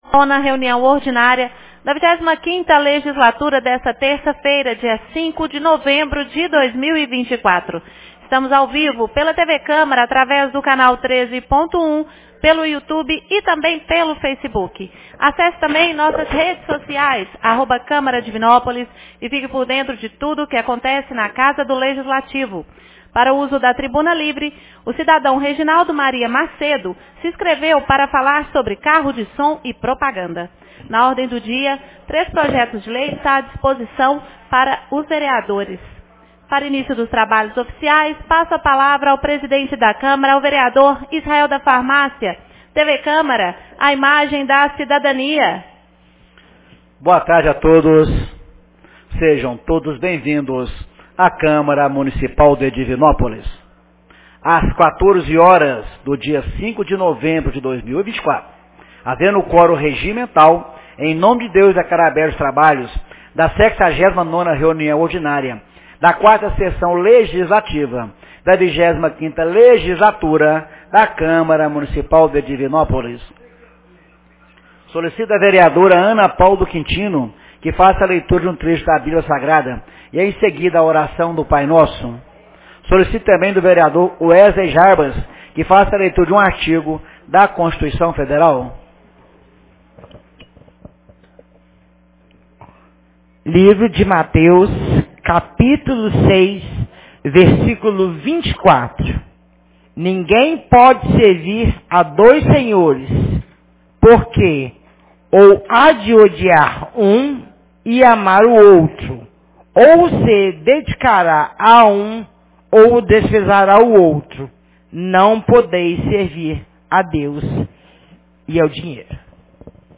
69ª Reunião Ordinária 05 de novembro de 2024 — Câmara Municipal